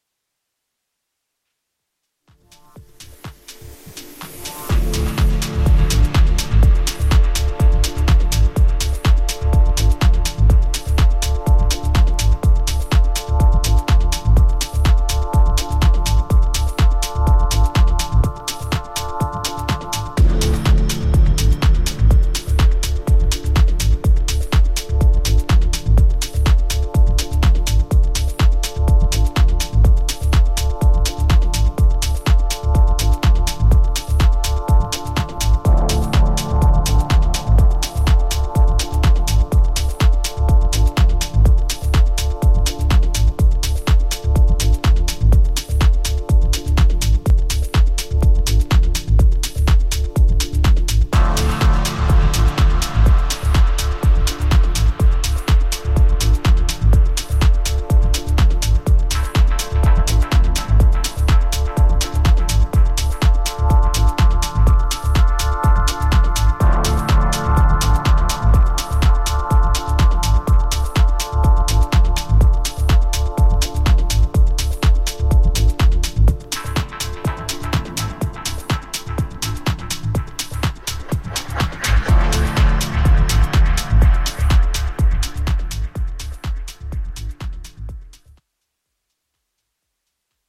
ジャンル(スタイル) TECH HOUSE / MINIMAL HOUSE